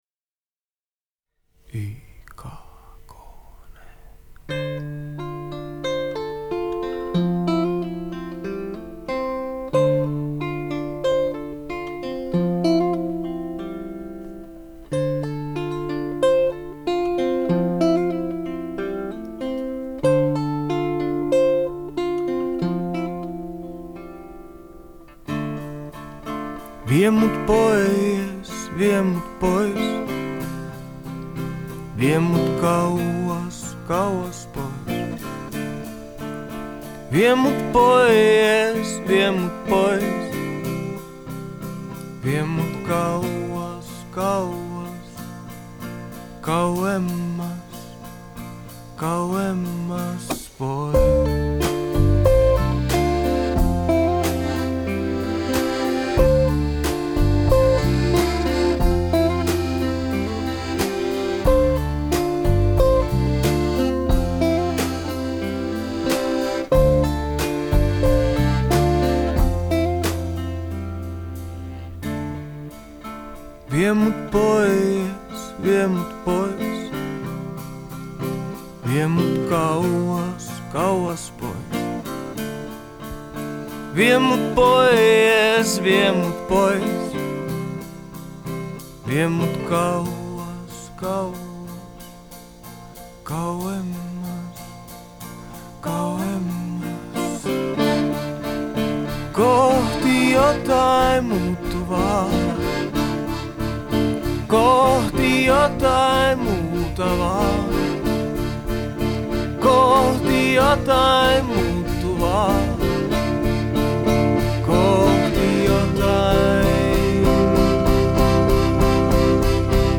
вокал и гитара
аккордеон и вокал
- барабаны
Genre: Folk, World